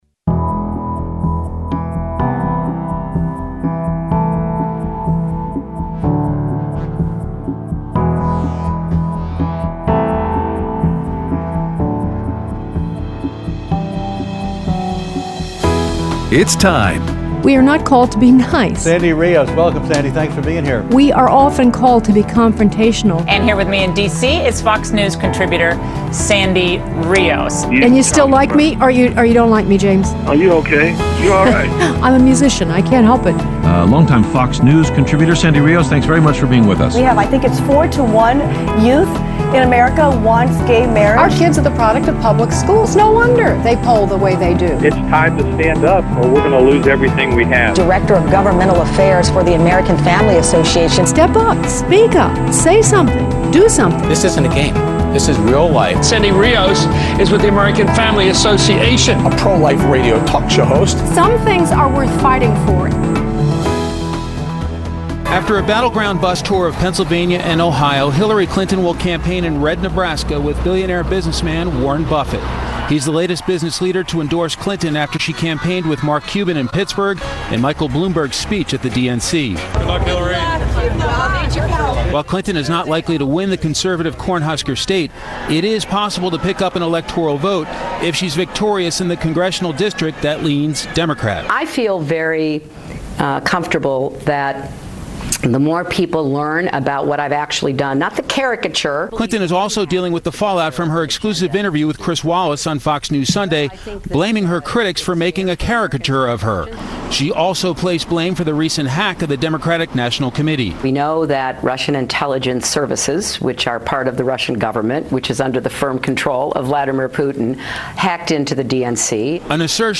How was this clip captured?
Election news and your phone calls on Mr. Khan